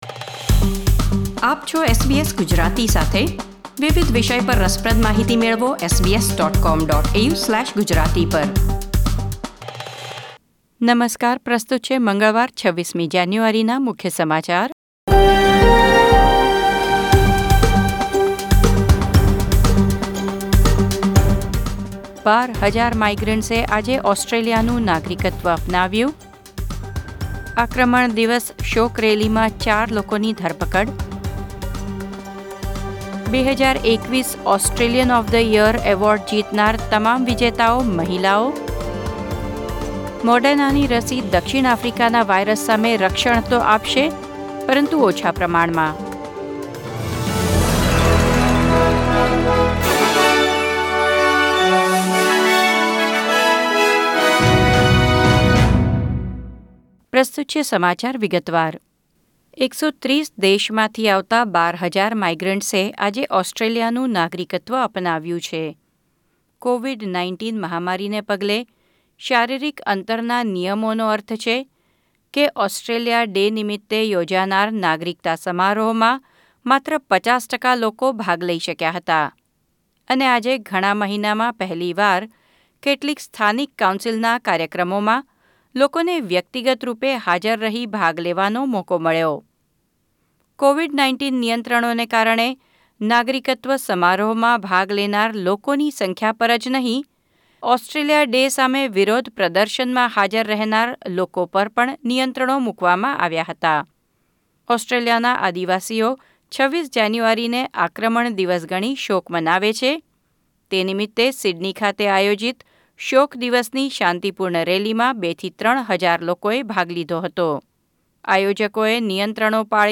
SBS Gujarati News Bulletin 26 January 2021